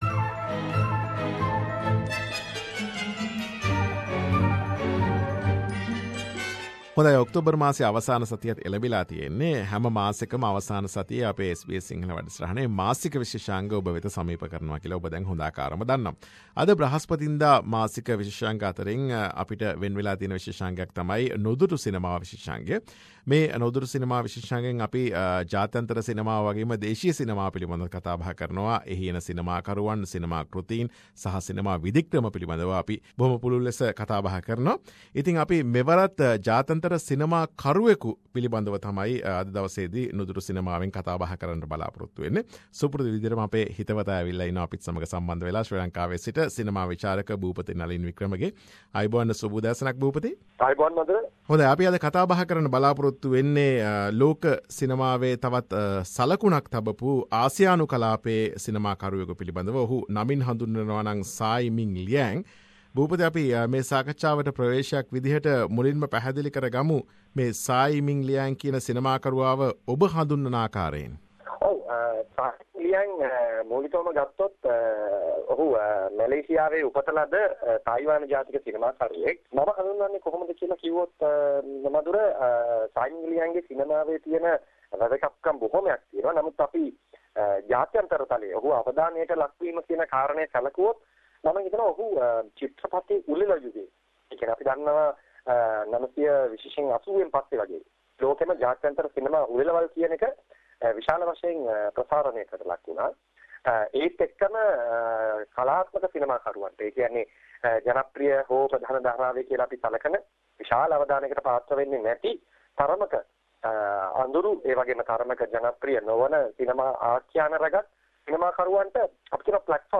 අප සමග කතා බහට